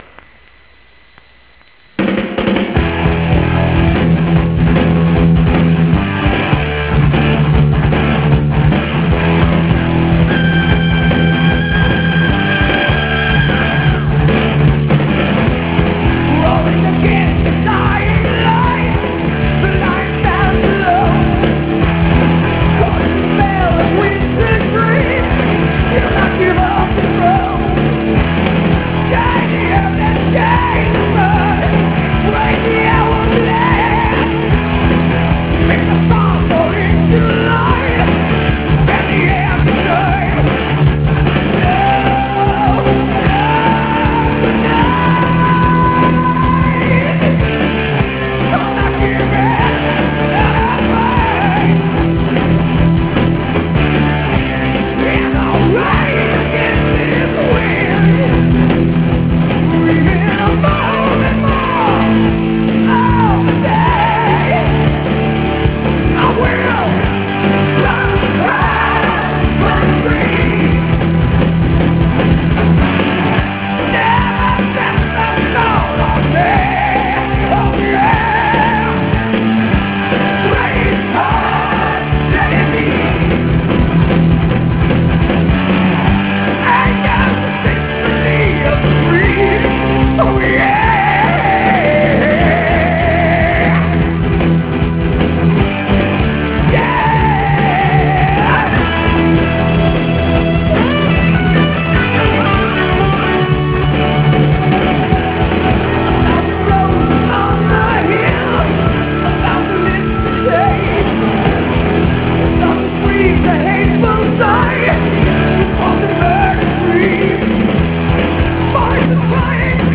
epic yet fundamentally sad metal ride
vocals, keyboard
guitar
bass guitar
drums